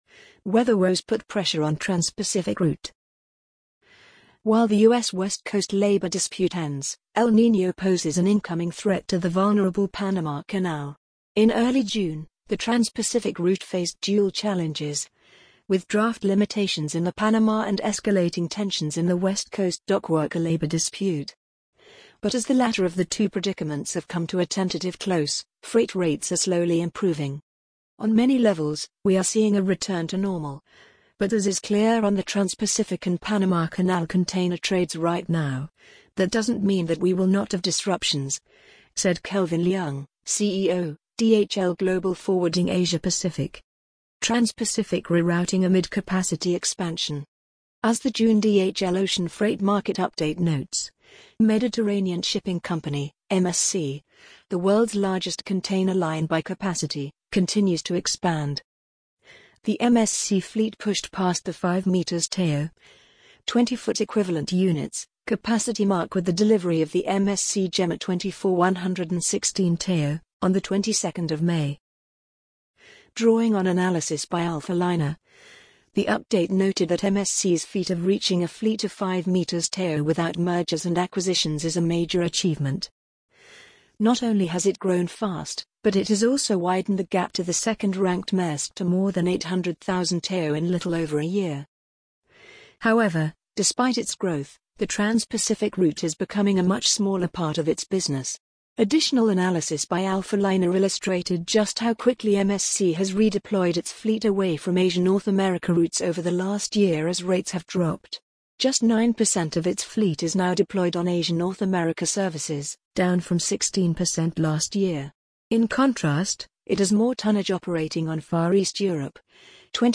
amazon_polly_36109.mp3